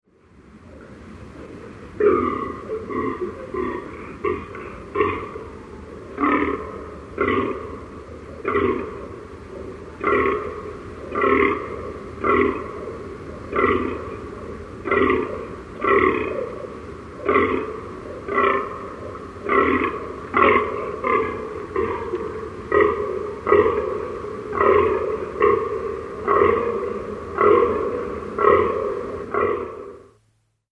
На этой странице собраны звуки лани — от мягких шагов по траве до тихих голосовых сигналов.
Звуки лани в дикой природе и ее естественная среда обитания